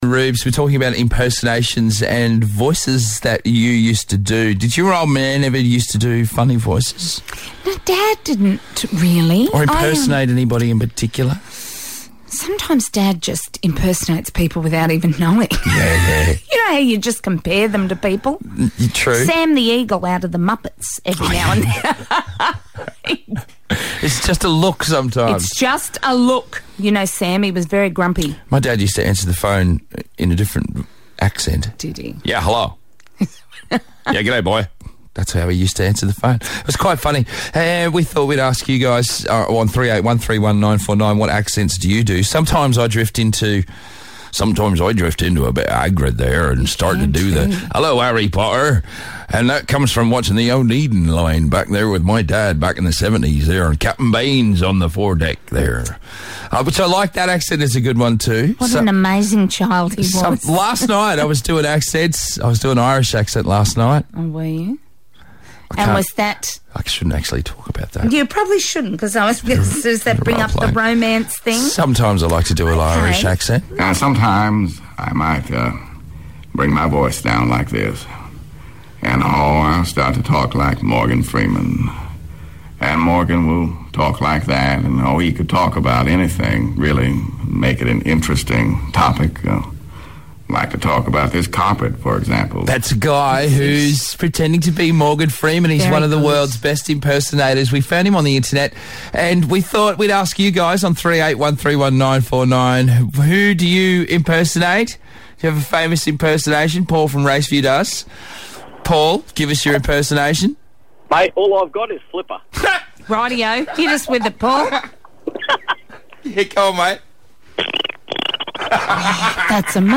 Impersonations